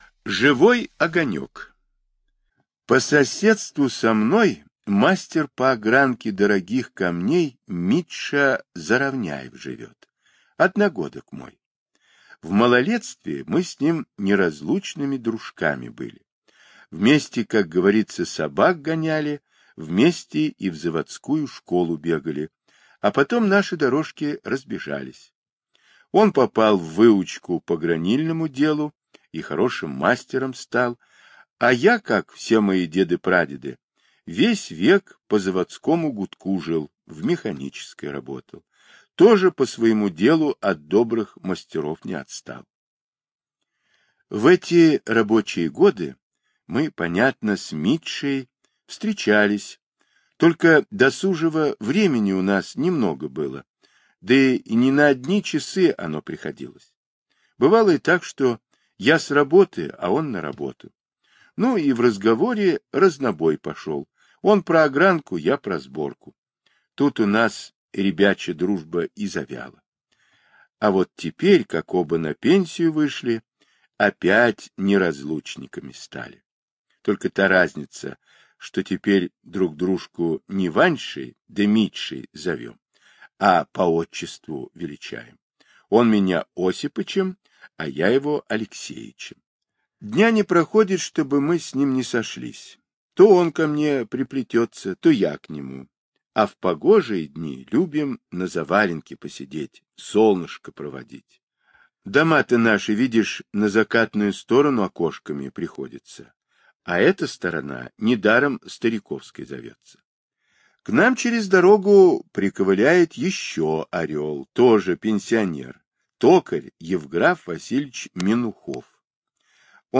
Живой огонёк - аудиосказка Павла Бажова - слушать онлайн